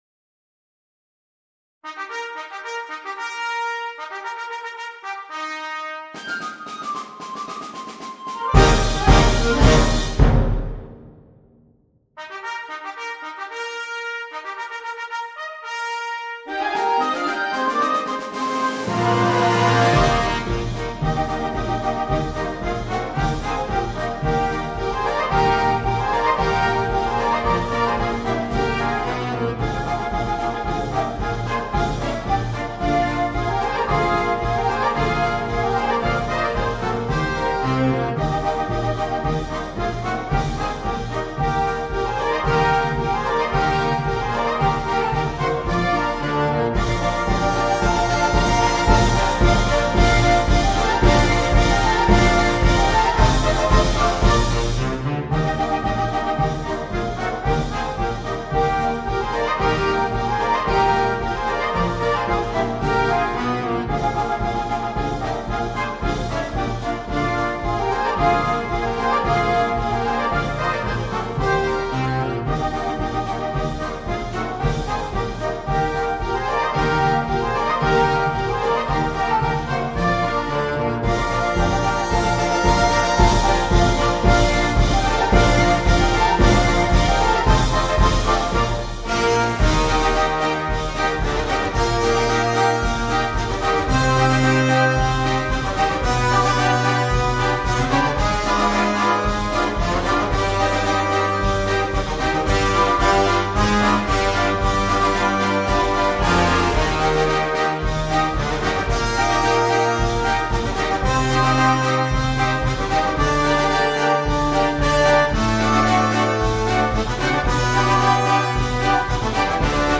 Grande marcia italiana
celebre marcia militare